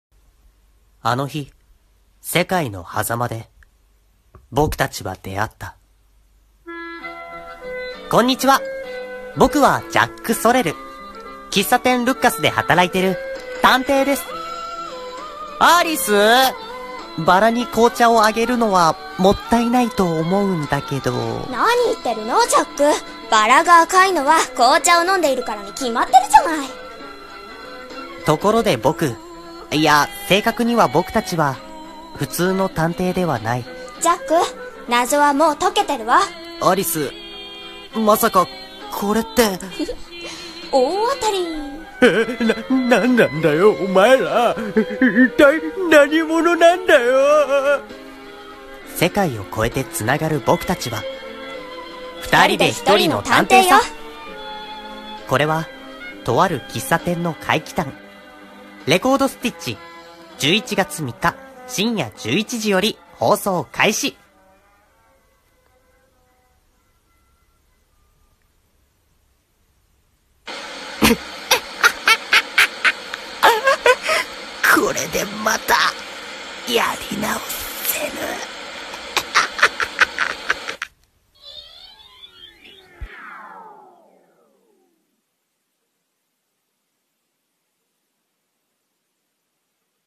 【声劇台本】偽アニメ予告CM 「レコード・ステッチ」